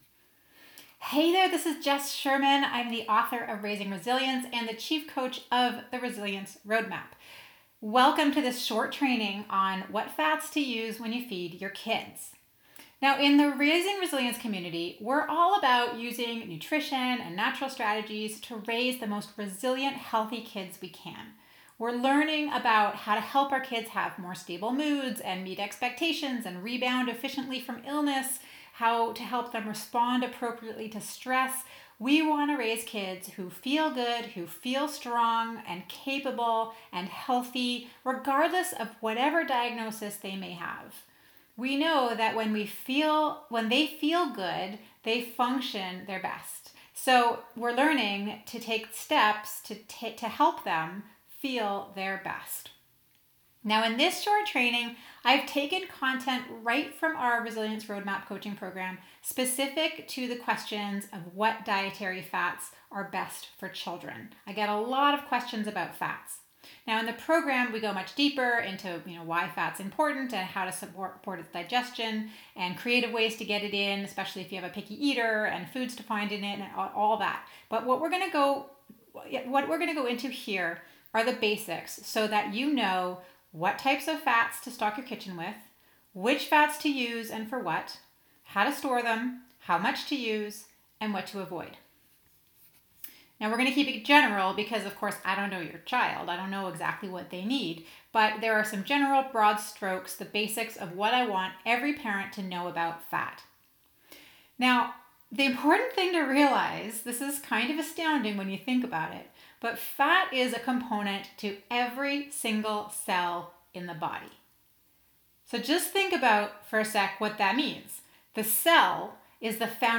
fats-mini-lesson.mp3